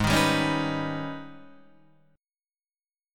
G# Minor Major 11th